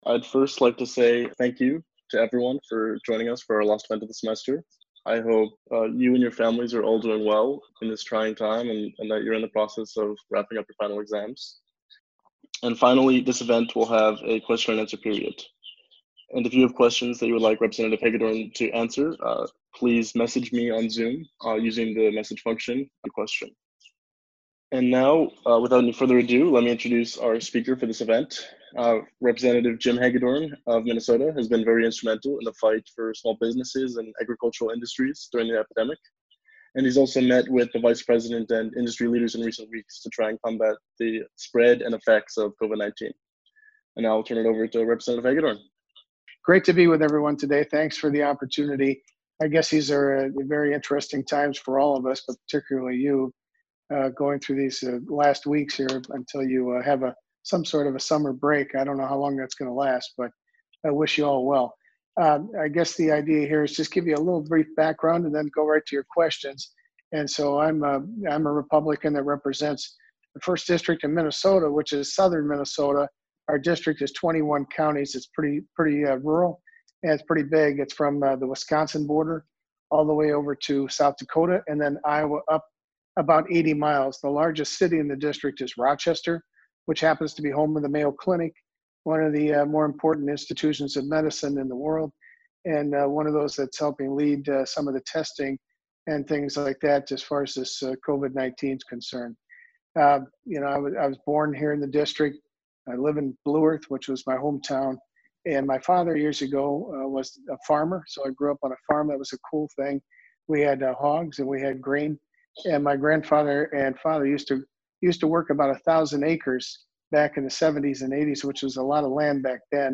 On Friday May 8, 2020, The Princeton Tory joined with Princeton College Republicans and the Cliosophic Party for a conversation with Representative Jim Hagedorn (R-MN), member of the House Committees on Agriculture and Small Businesses, to discuss his current political outlook, the conservative response to coronavirus, and his thoughts on rebuilding the American economy.